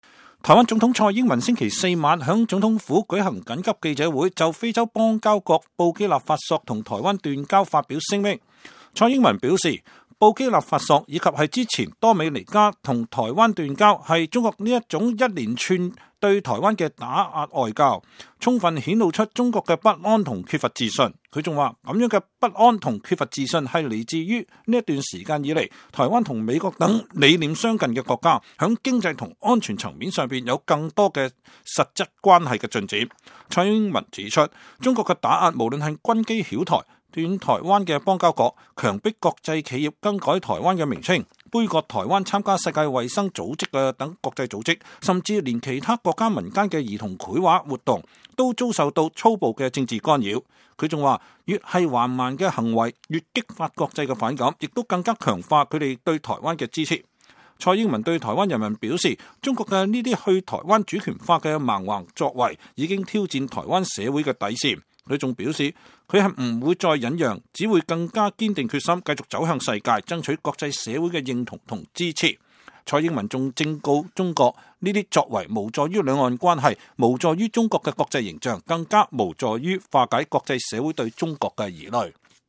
台灣總統蔡英文星期四（24日）晚在總統府舉行緊急記者會，就非洲邦交國布基納法索同台灣斷交發表聲明。蔡英文說，布基納法索，以及此前多米尼加同台灣斷交，是中國這一連串對台灣的外交打壓，充分顯露出中國的不安與缺乏自信。